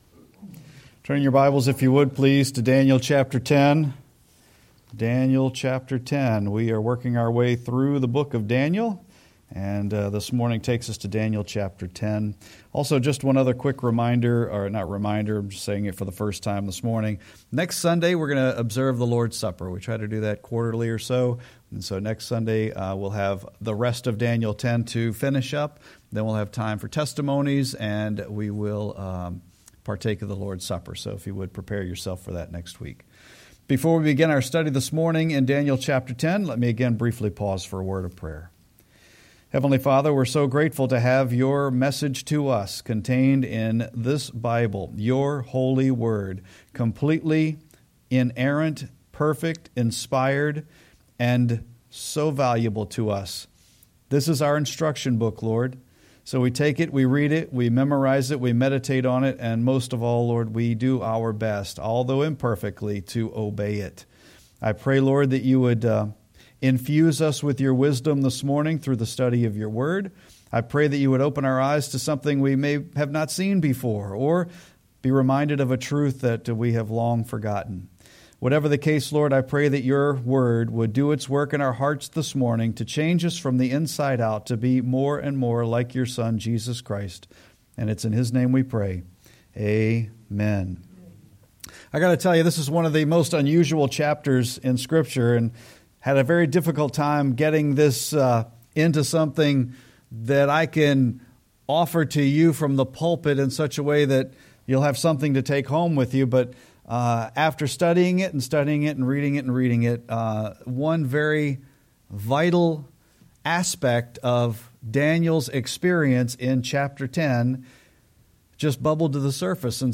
Sermon-2-16-25.mp3